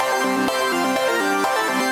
SaS_Arp05_125-C.wav